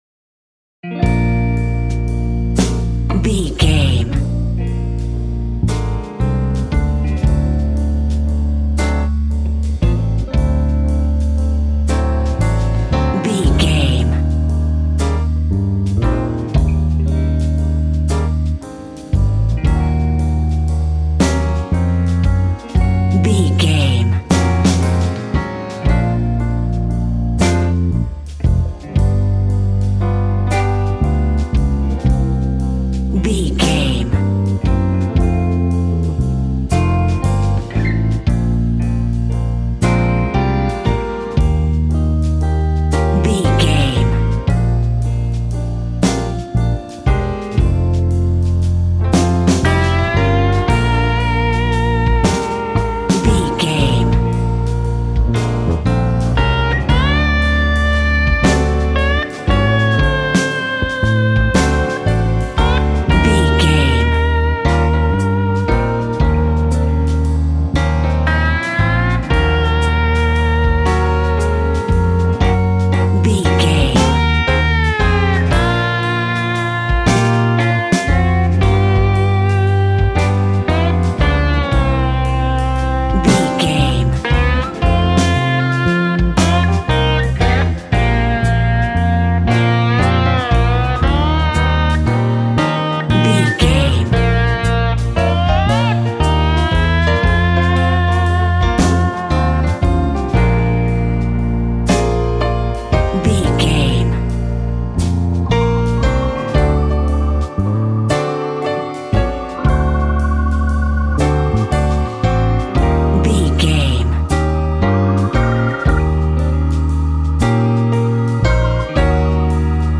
Ionian/Major
B♭
hard
sad
mournful
electric guitar
bass guitar
drums
electric organ